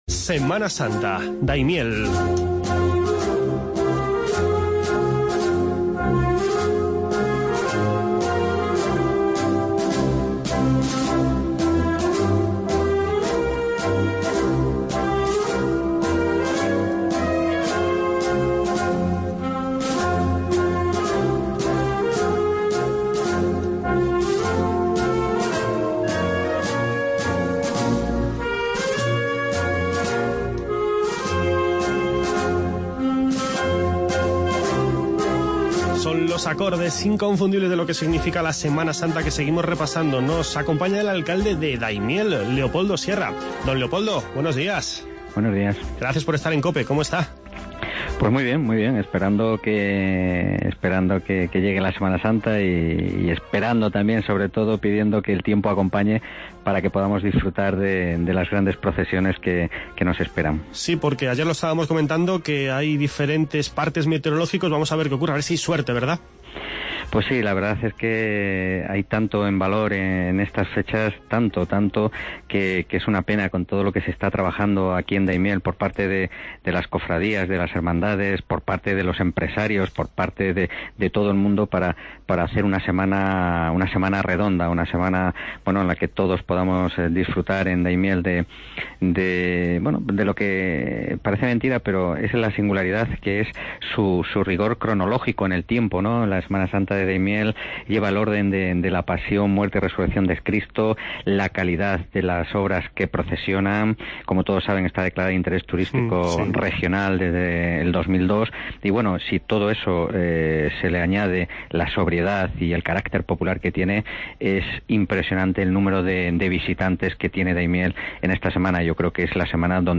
Escuche las entrevistas con el alcalde de Daimiel, Leopoldo Sierra, y con Juan Gil Gutiérrez, primer edil de El Bonillo, sobre las Semanas Santas de ambas poblaciones. Además, hablamos del XIV Circuito de Carreras Populares y IX Circuito MTB con Óscar Pinar, diputado de Deportes y Medio Ambiente de la Diputación Provincial de Cuenca y concejal de Deportes del Ayuntamiento de Casasimarro.